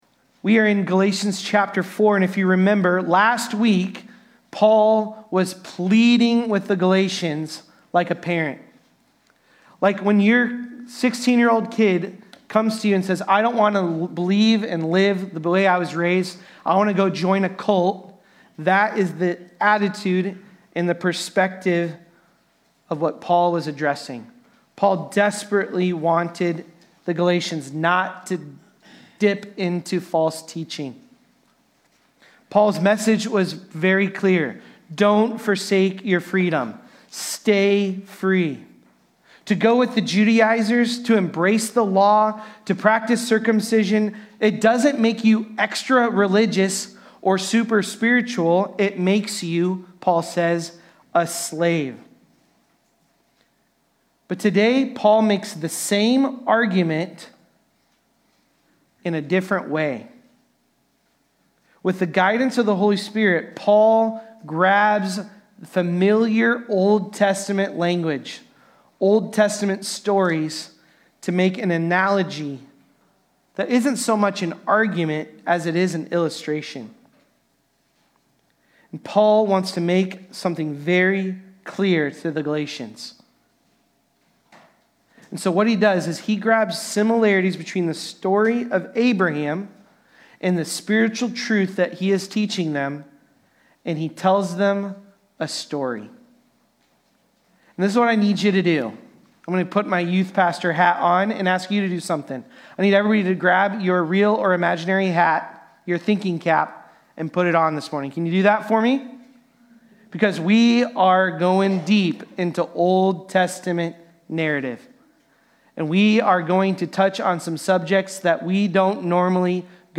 Galatians-4.21-5.1-Sermon-Audio.mp3